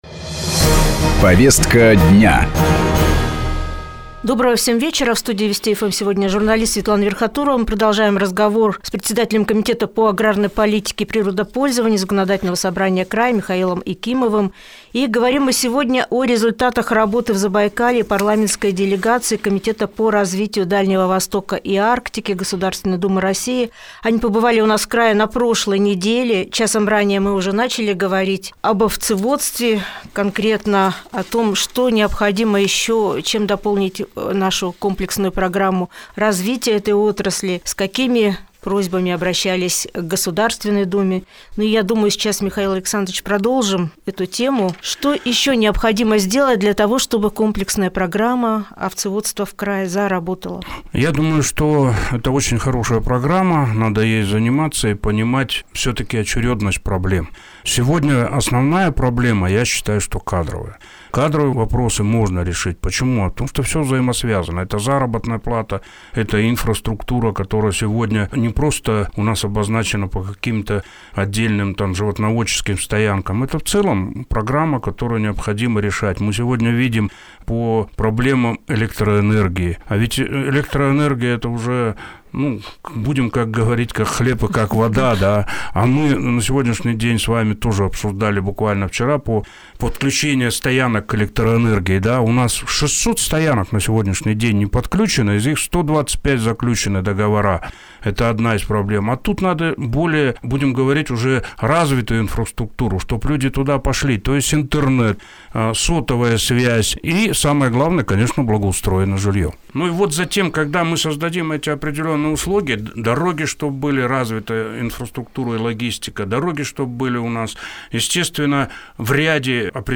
О результатах работы парламентской делегации комитета по развитию Дальнего Востока и Арктики в Забайкалье – интервью с главой комитета по аграрной политике и природопользованию Михаилом Якимовым